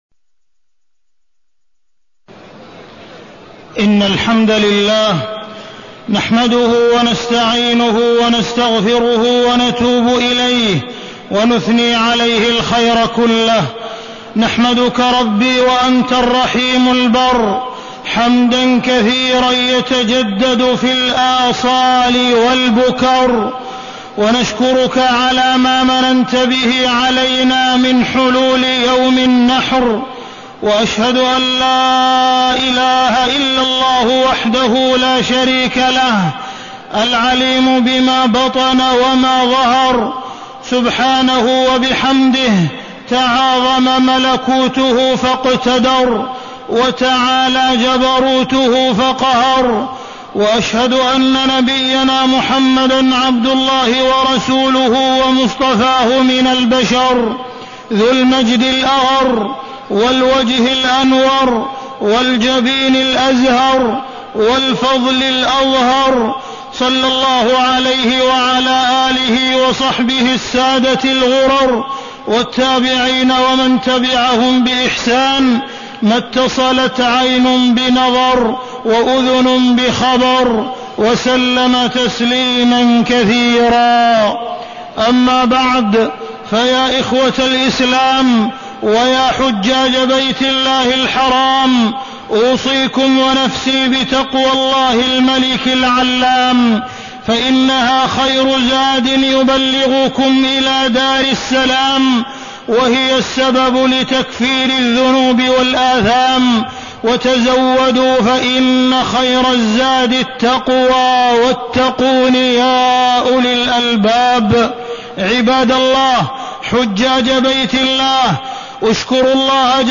تاريخ النشر ١٠ ذو الحجة ١٤٢٢ هـ المكان: المسجد الحرام الشيخ: معالي الشيخ أ.د. عبدالرحمن بن عبدالعزيز السديس معالي الشيخ أ.د. عبدالرحمن بن عبدالعزيز السديس أعمال يوم النحر